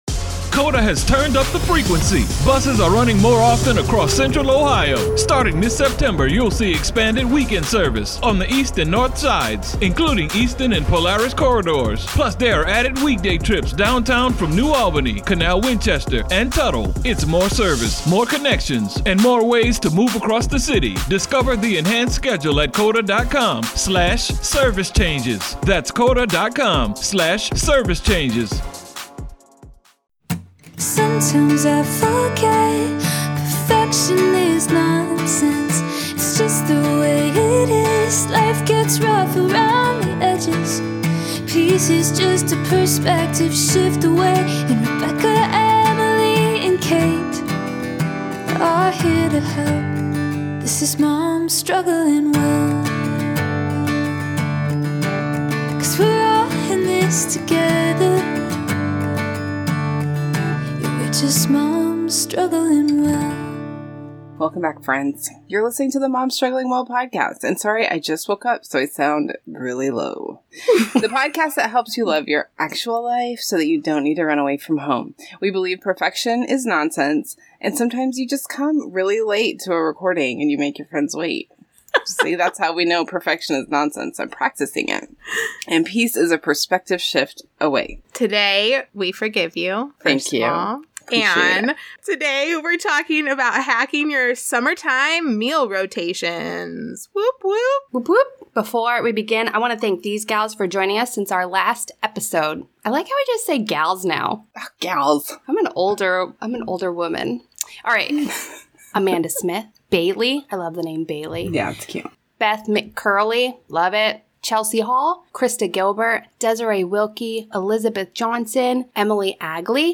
This particular episode is like an audio version of a Pinterest scroll but with friends and lots of laughter.